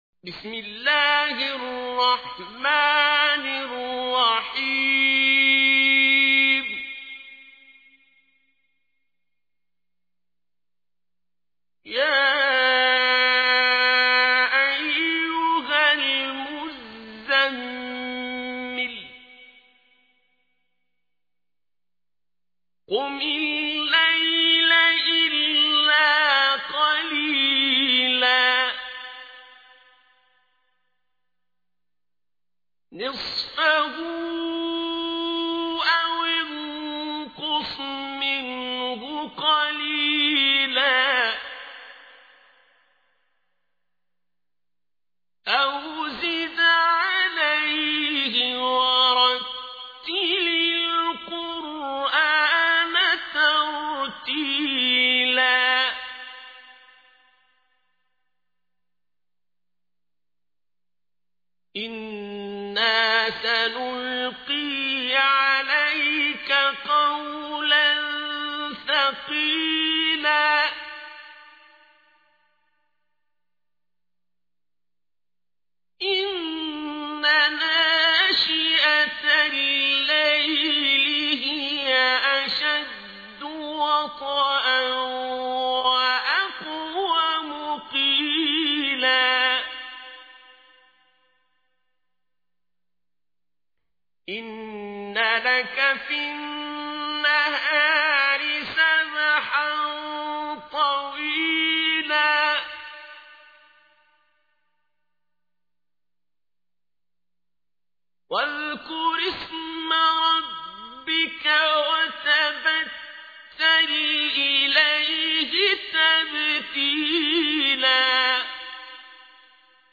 تحميل : 73. سورة المزمل / القارئ عبد الباسط عبد الصمد / القرآن الكريم / موقع يا حسين